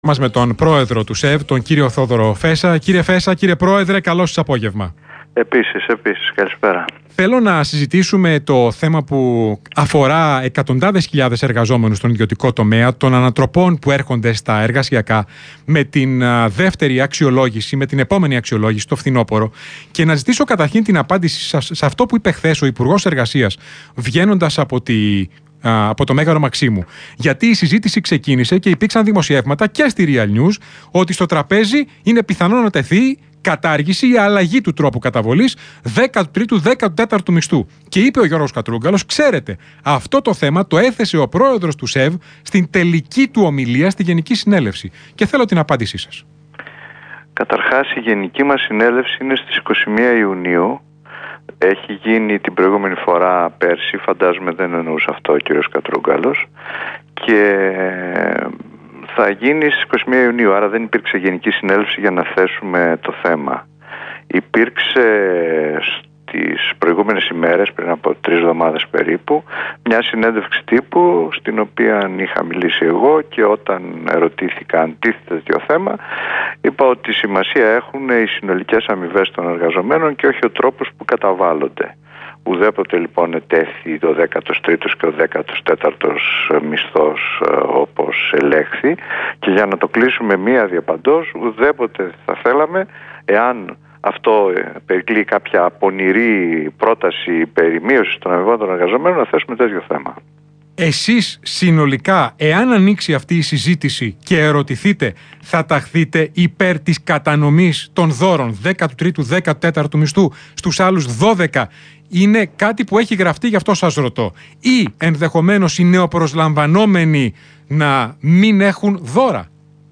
Συνέντευξη του Προέδρου του ΣΕΒ, κ. Θεόδωρου Φέσσα στον Ρ/Σ Real FM για τα εργασιακά, 7/6/2016